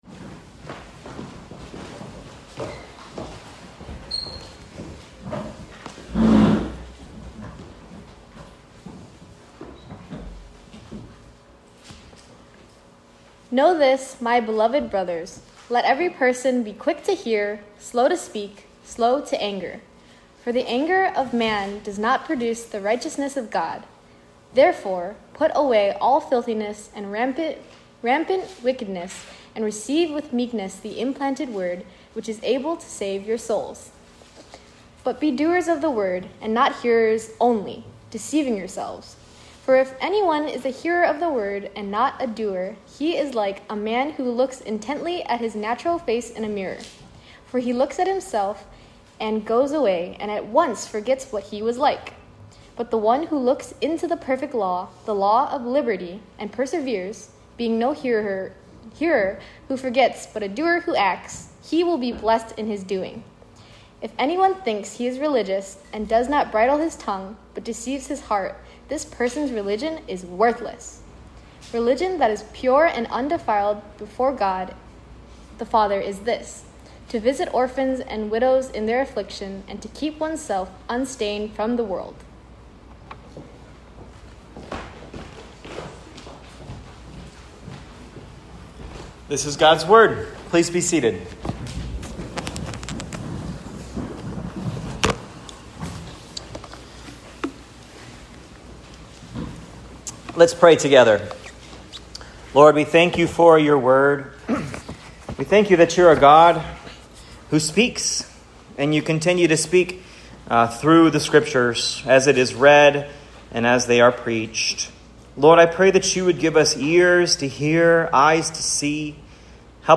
“Hearers AND Doers” (James 1:19-27 Sermon)
(Preached at Cross of Christ Fellowship in Naperville, Illinois on 6/29/25).